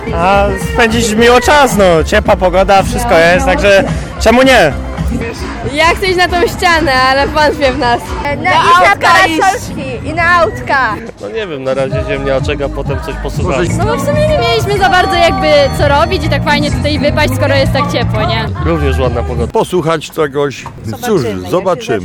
Wśród rzeczy, które zachęciły ich do przyjścia, uczestnicy imprezy wymieniali słoneczną pogodę i atrakcje.